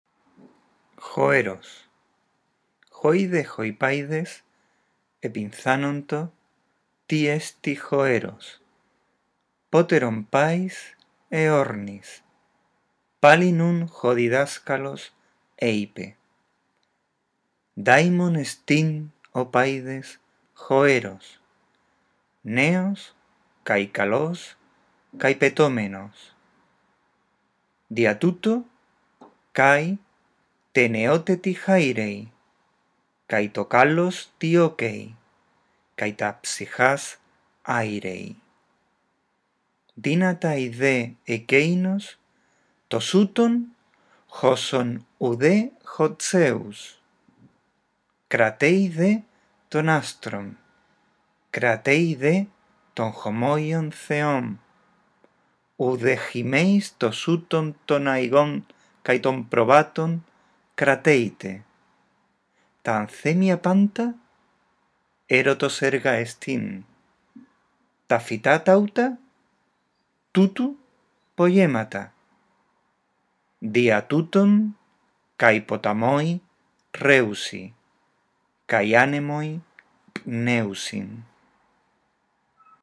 En este archivo de audio está la lectura del texto completo; escucha con atención y repite la lectura del fragmento de Longo.